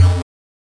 新鲜包装 " Bass01
Tag: 低音 畸变 电子 打击乐器